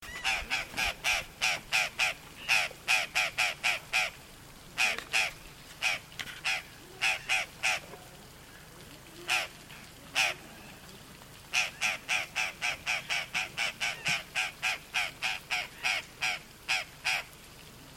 Urraca Morada (Cyanocorax cyanomelas)
Varios individuos en el camping, curiosos y oportunistas
Localidad o área protegida: Parque Nacional Chaco
Certeza: Observada, Vocalización Grabada
Urraca-Morada.mp3